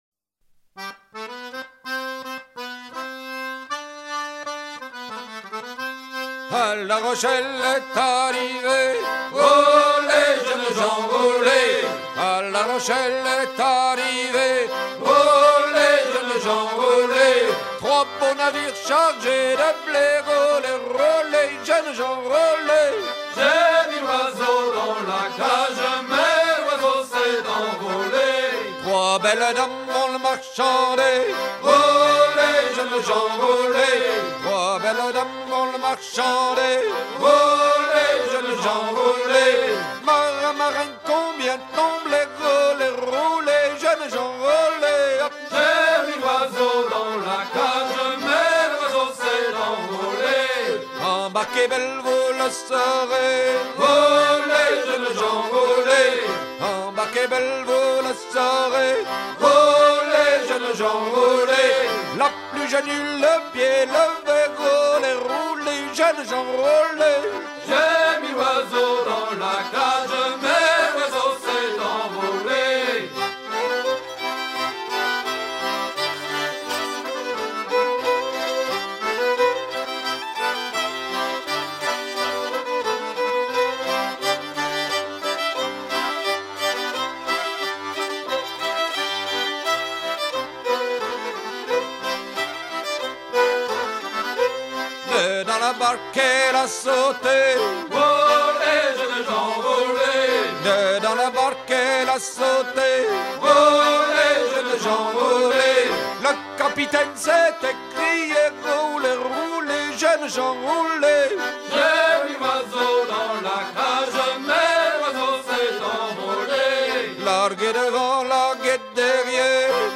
Version recueillie en 1975 auprès de marins fécampois harenguiers et terre-neuvas
gestuel : à virer au guindeau ; danse : ronde à trois pas ;
Genre laisse
Pièce musicale éditée